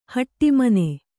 ♪ haṭṭi mane